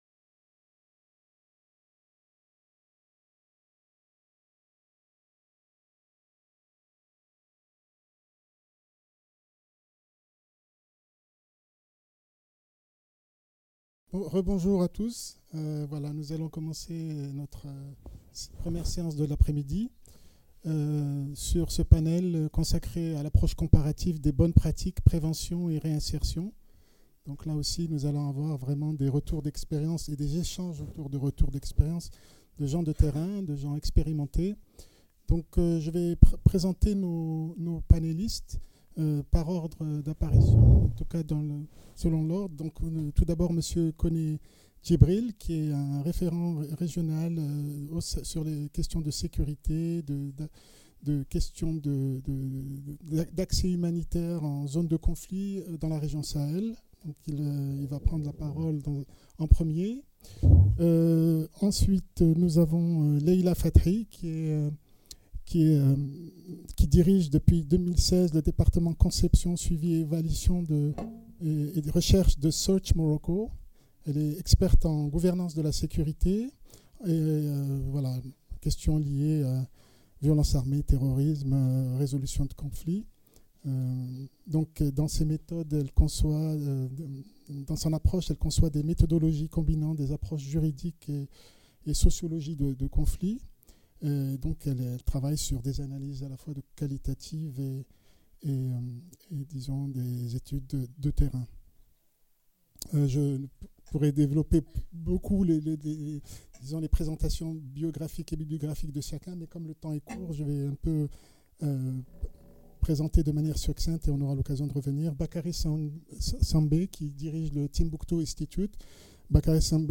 Approche comparative des bonnes pratiques - prévention et réinsertion - Colloque international Rabat 2019 | Canal U